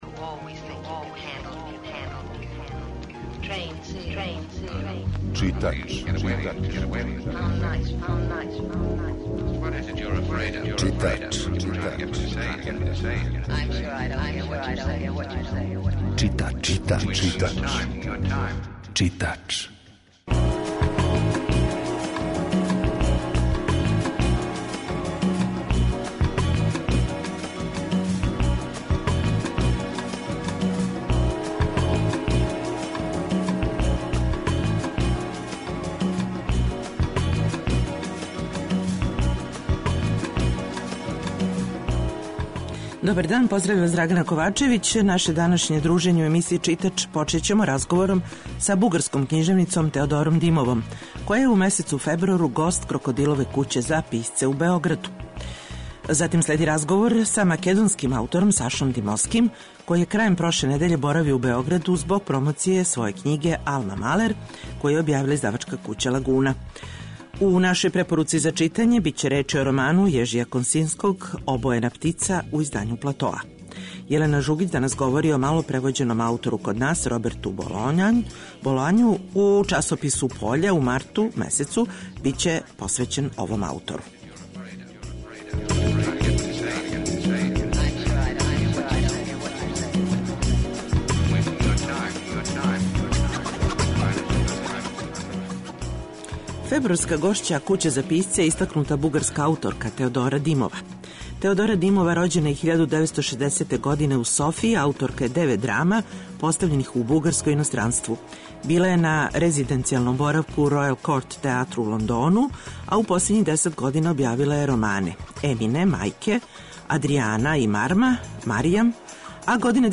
Емисија је колажног типа